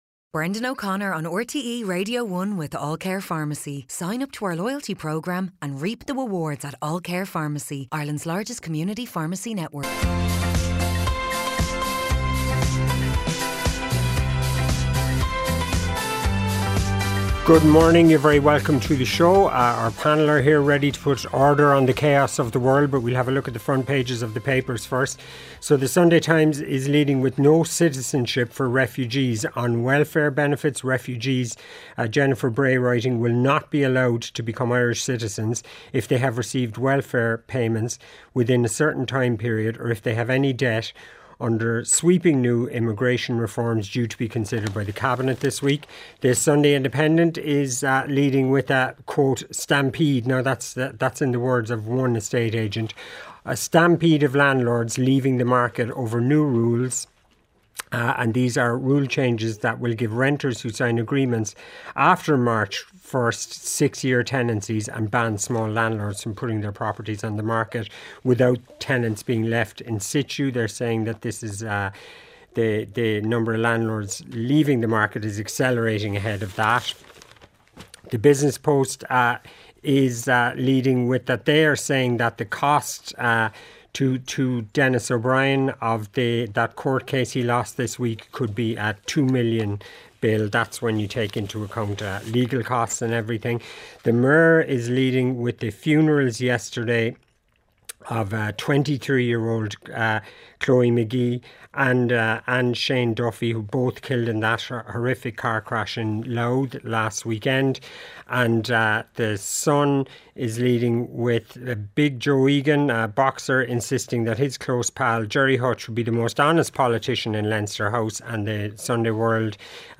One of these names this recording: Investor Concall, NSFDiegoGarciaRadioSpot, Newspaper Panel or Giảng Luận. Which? Newspaper Panel